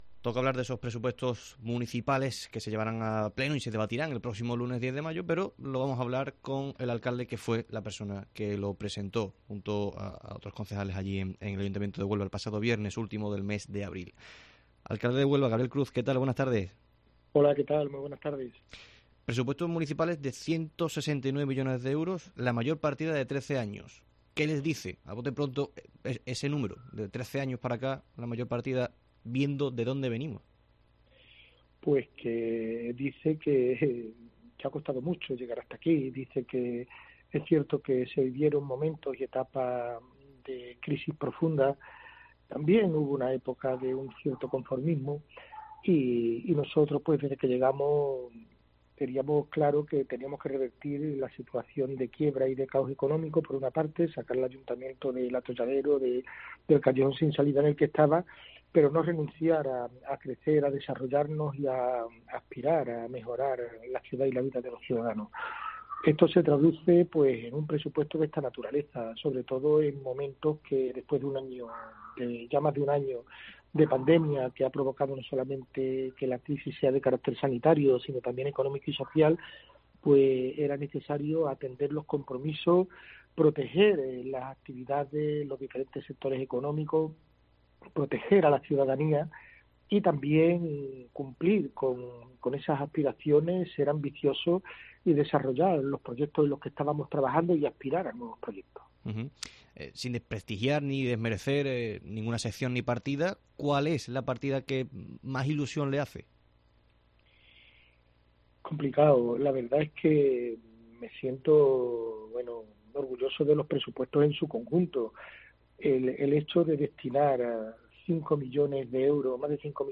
El alcalde de Huelva, Gabriel Cruz, ha atendido la llamada de COPE Huelva para responder a las preguntas relacionadas con los presupuestos...